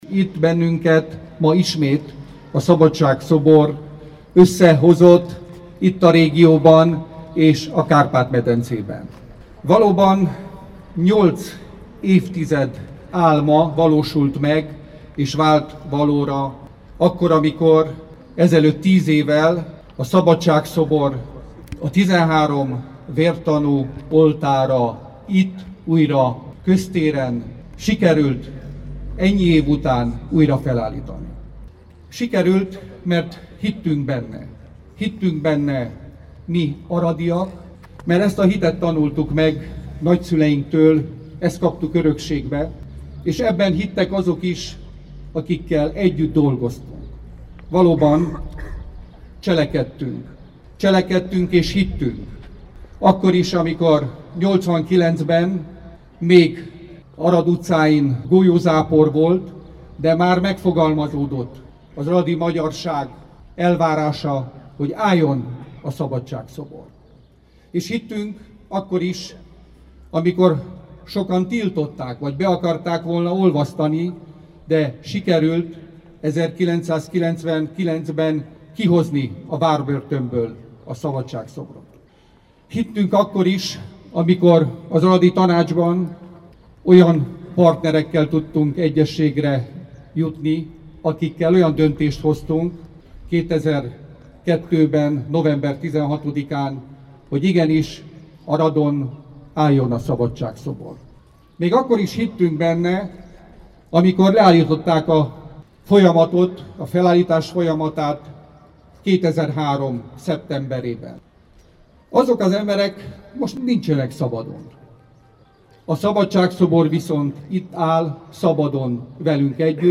„Akik gátoltak, ma nincsenek szabadon” – Bognár Levente beszéde a Szabadság-szobor újraállításának 10. évfordulóján [AUDIÓ]
Tiz eve kozteren a Szabadsag-szobor (6)„Akik akadályozták a Szabadság-szobor újraállítását, ma nincsenek szabadon. A szabadság-szobor viszont itt áll velünk szabadon” – fogalmazott Zala György alkotása újraállításának 10. évfordulóján Bognár Levente Arad megyei RMDSZ-elnök, a város alpolgármestere. A szobornál tartott péntek délutáni ünnepségen elmondott beszéde meghallgatható itt.